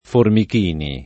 Formichini [ formik & ni ]